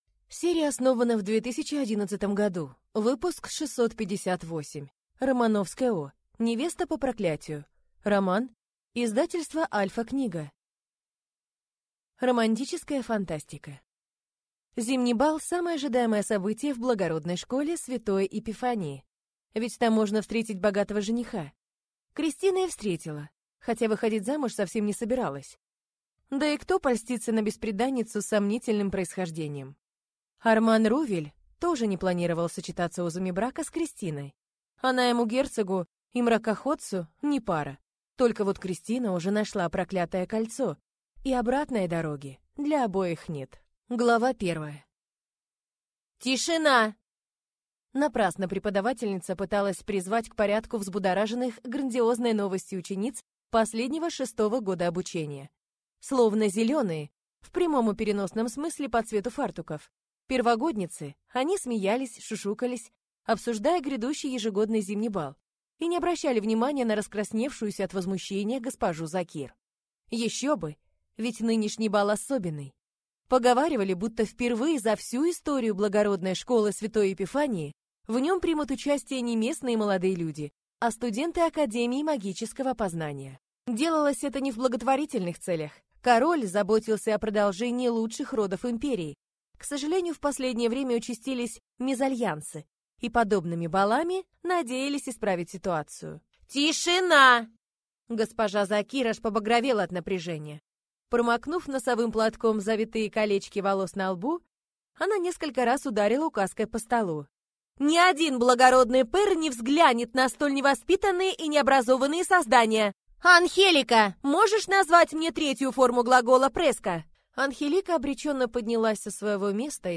Аудиокнига Невеста по проклятию | Библиотека аудиокниг